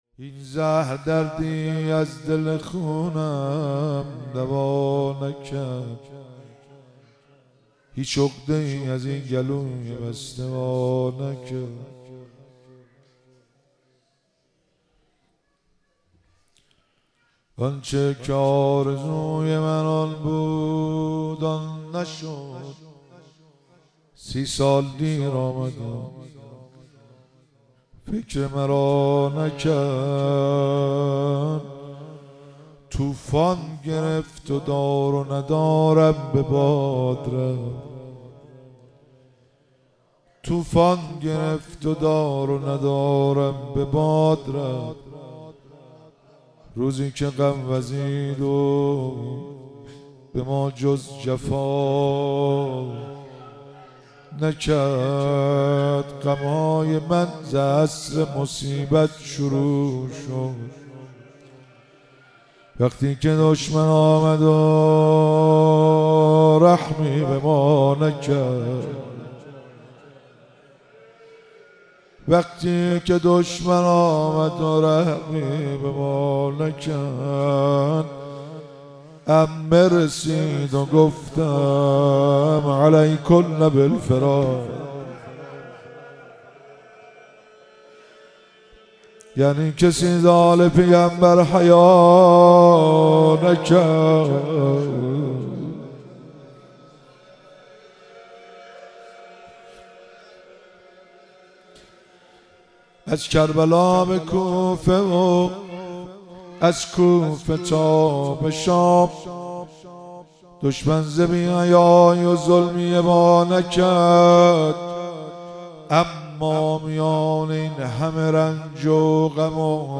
مراسم مناجات خوانی شب چهاردهم ماه رمضان 1444